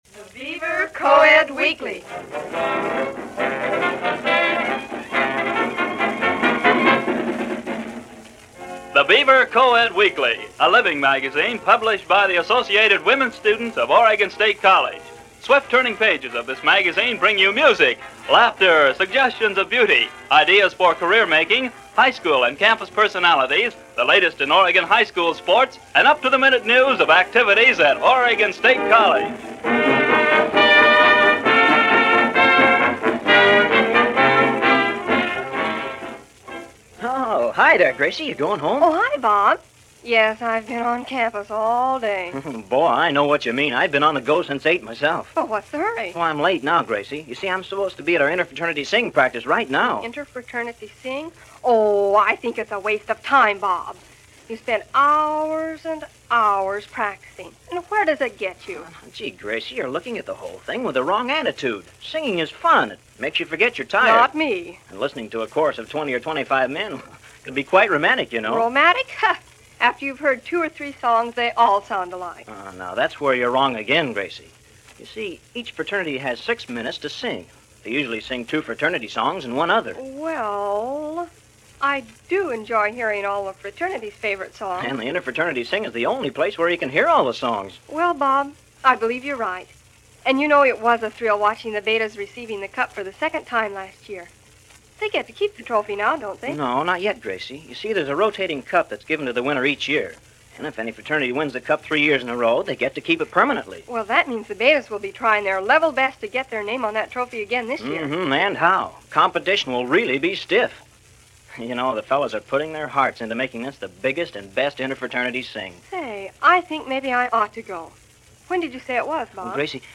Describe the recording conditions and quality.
College Radio In The 1940s - Past Daily Weekend Gallimaufry - a segment of Oregon Coed Weekly produced by the Students at Oregon State.